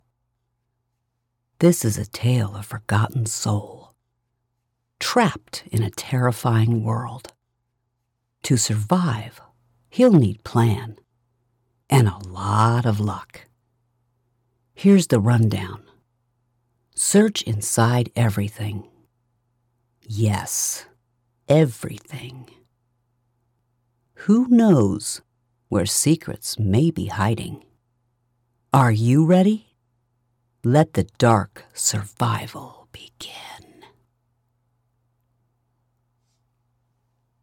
0623Storyteller_Voice_for_Horror_Video_Game_Trailer.mp3